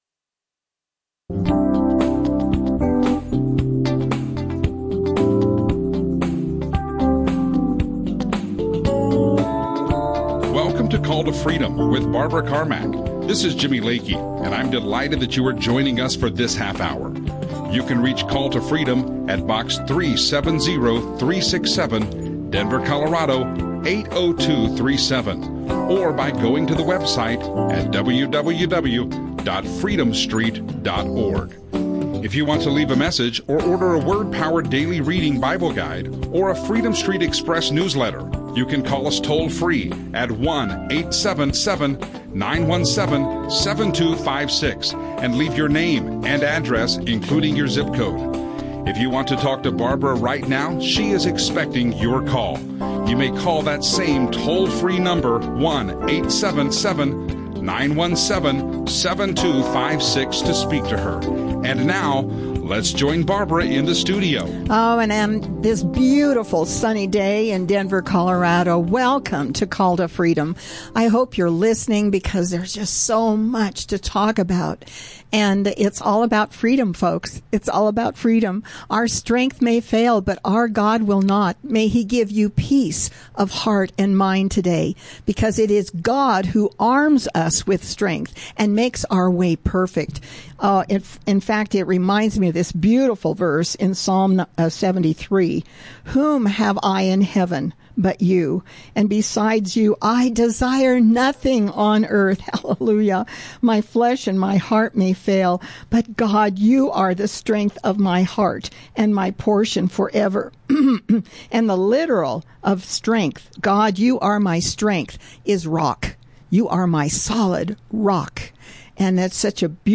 Christian talk show